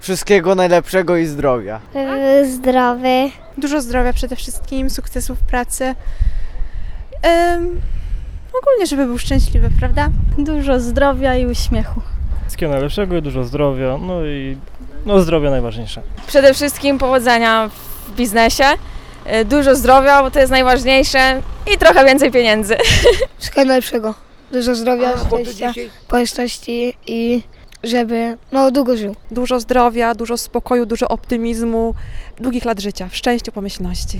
zapytała mieszkańców pałuk, czego życzą ojcom w dniu ich święta.